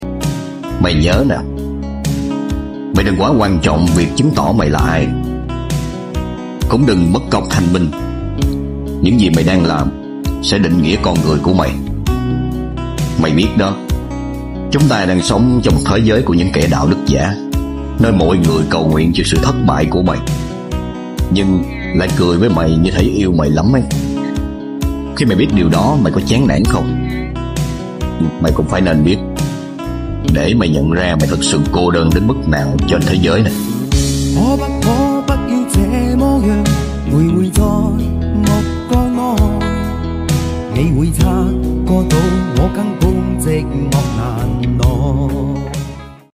giọng đọc òm òm nhưg mà lại hay và họp với câu từ ! 😊